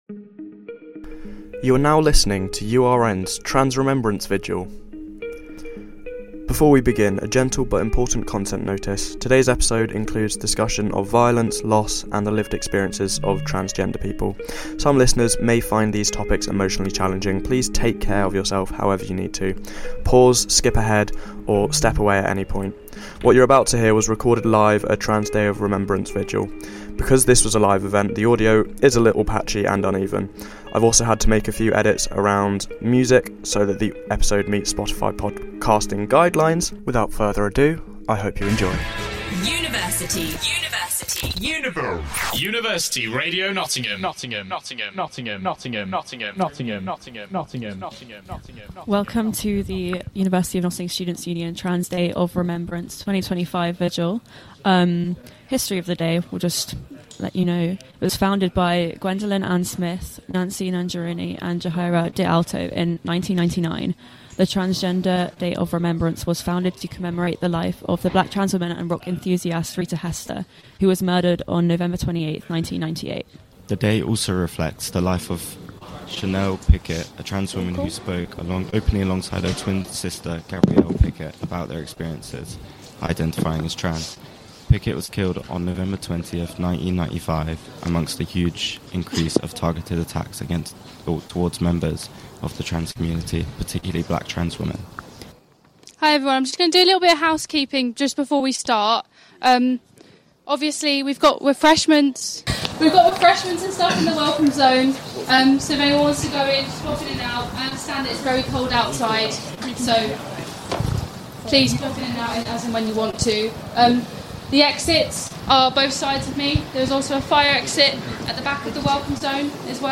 On the 20th November, UONSU hosted a Transgender Rememberance Vigil. This is URN's Broadcast of it.